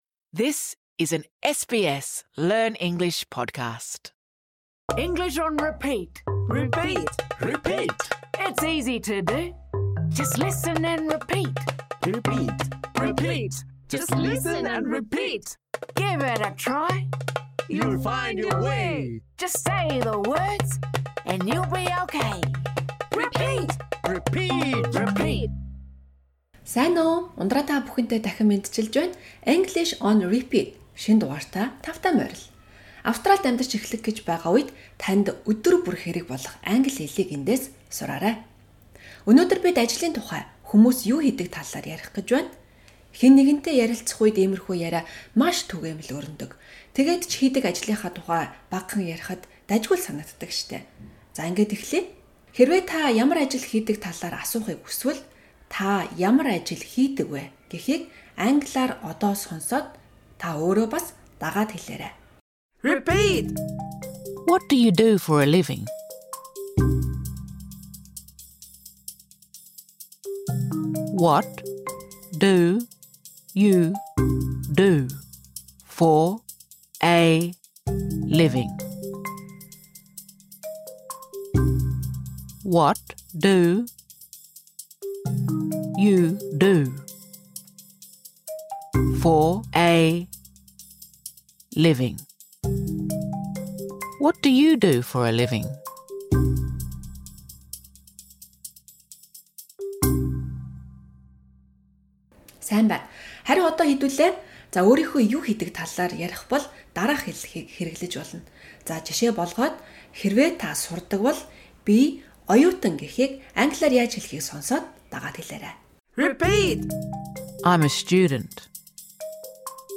Энэ бол Англи хэлийг эхнээс сурч байгаа хүмүүст зориулсан хялбаршуулсан хичээл.
Сонсоод, дагаад хэлээрэй: What do you do for a living?